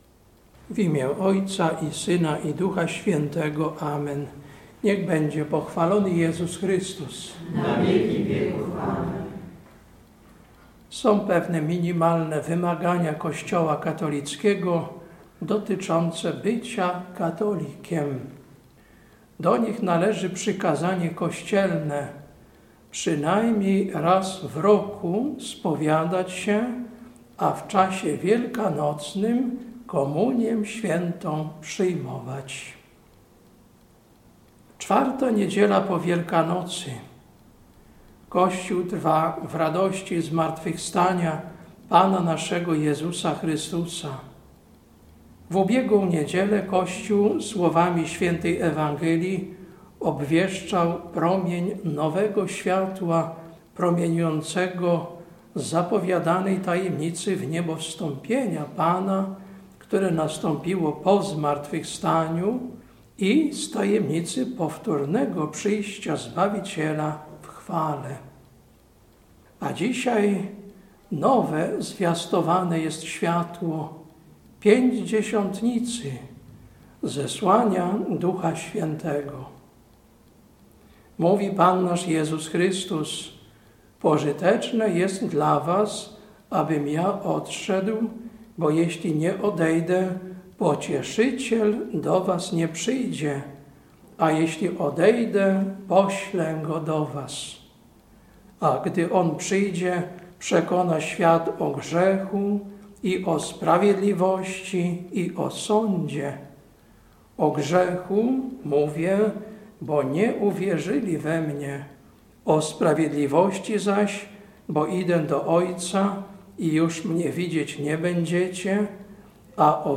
Kazanie na IV Niedzielę po Wielkanocy, 28.04.2024 Lekcja: Jk 1, 17-21 Ewangelia: J 16, 5-14